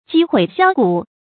積毀銷骨 注音： ㄐㄧ ㄏㄨㄟˇ ㄒㄧㄠ ㄍㄨˇ 讀音讀法： 意思解釋： 積毀：不止一次的毀謗；銷：熔化。